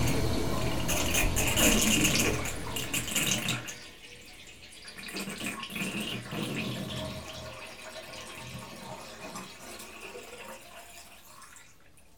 bath5.wav